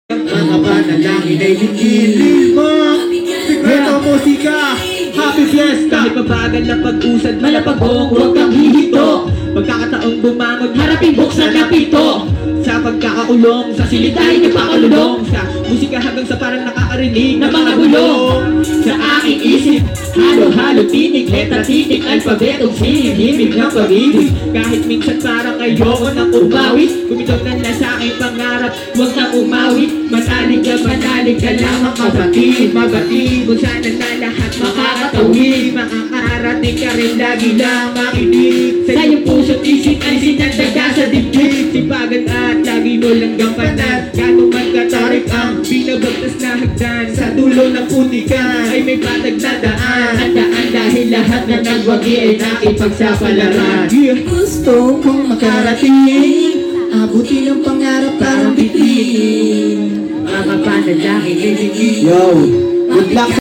Happy Fiesta Baranggay Manggahan Pasig